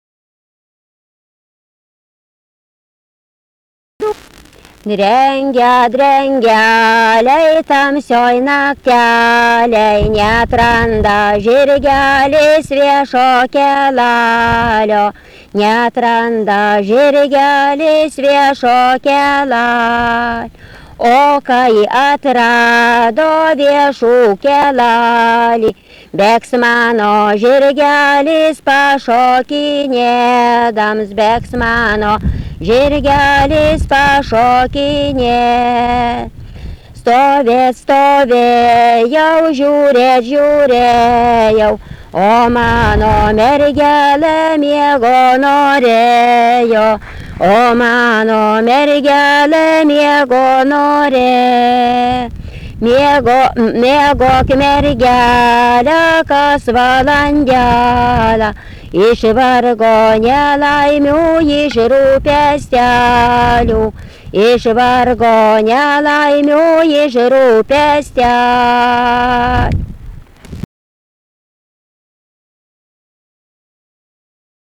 daina, vaikų
Erdvinė aprėptis Pociškė
Atlikimo pubūdis vokalinis